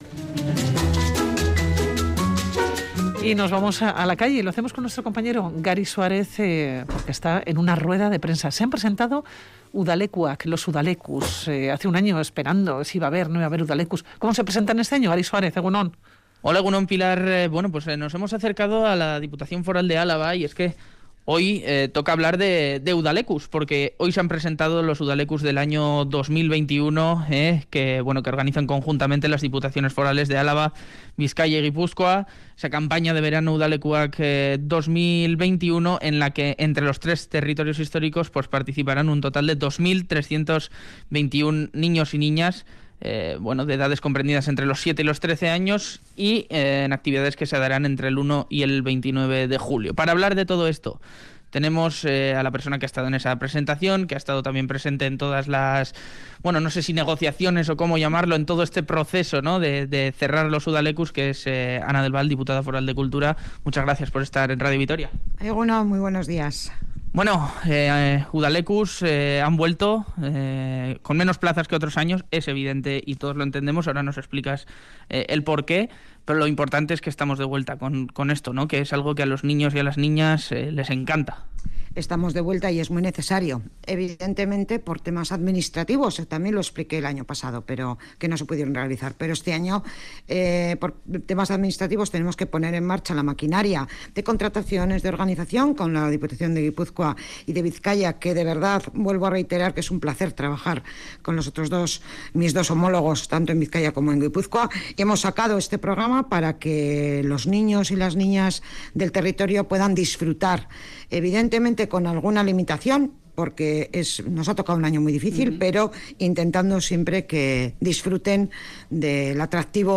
Audio: Charlamos con la Diputada Foral de Cultura, Ana del Val, que nos da los detalles de cómo se desarrollará el programa de Udalekuak en verano de 2021.